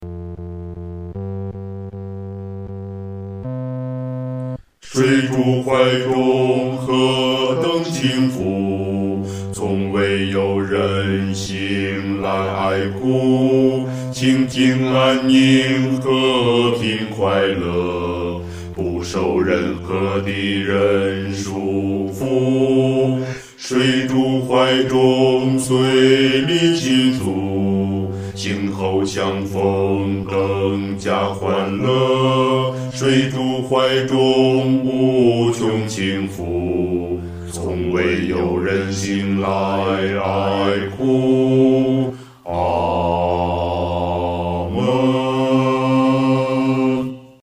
男低
其旋律、和声构成无比宁静的气氛，在丧礼中给人莫大的安慰。